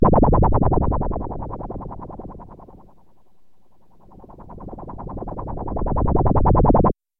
Звук броска бумеранга в бесконечную пустоту